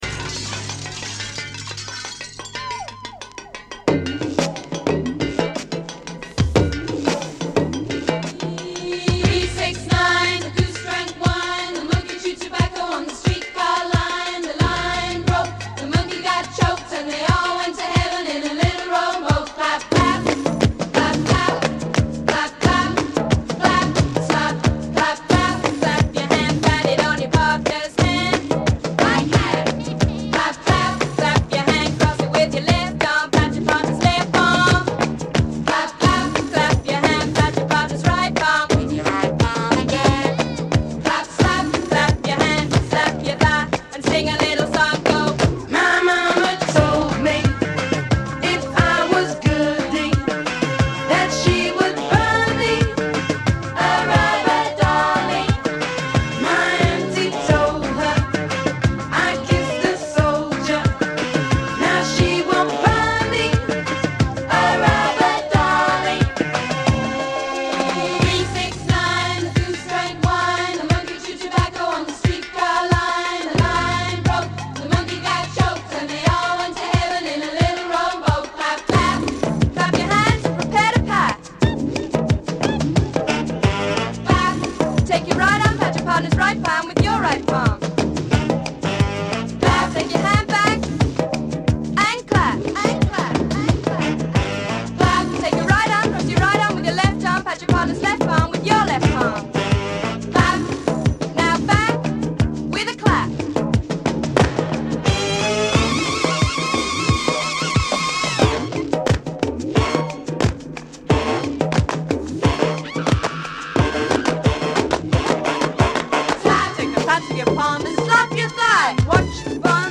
ウキウキ感のある展開がたまりません！